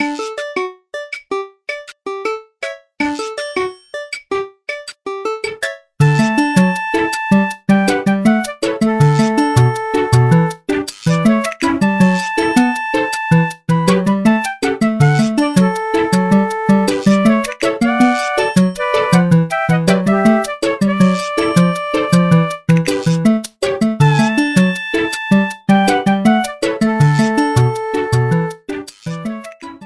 It is a rearrangement of the overworld theme